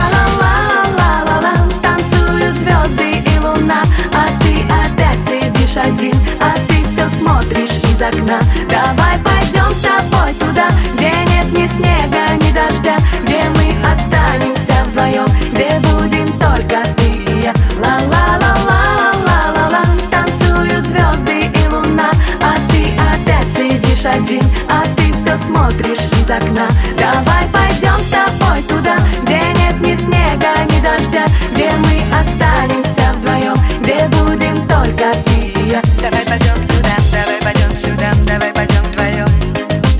танцевальные , поп